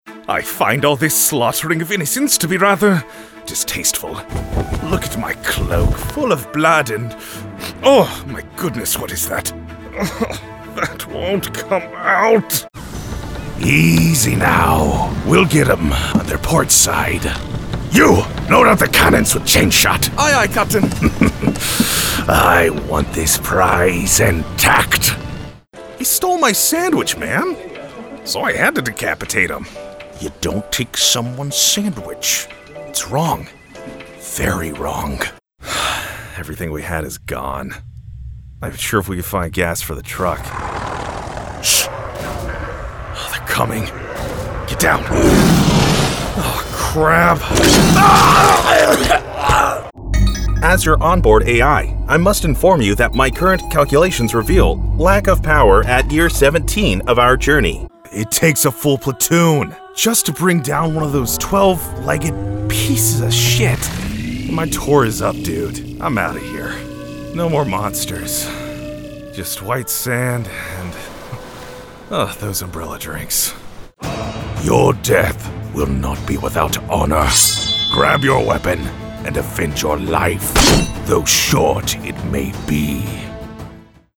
Video Game demo